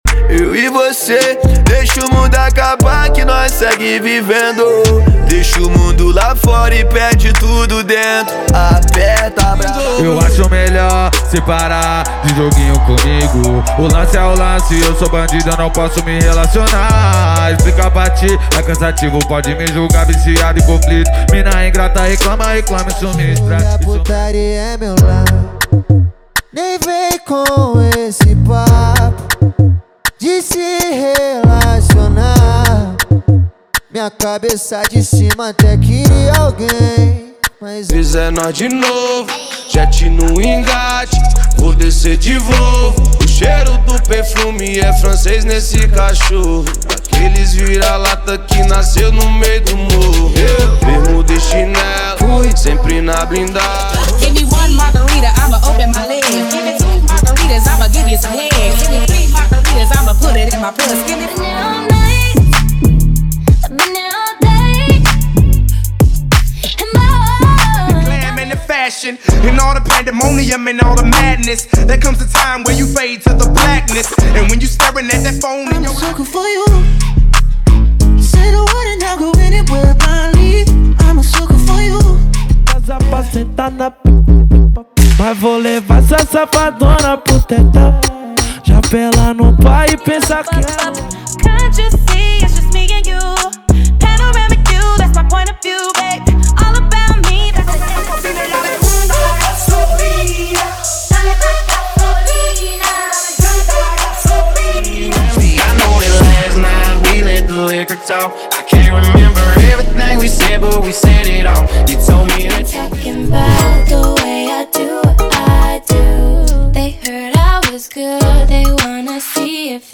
• Rap, Trap Nacional e Funk Ostentação = 50 Músicas
• Sem Vinhetas
• Em Alta Qualidade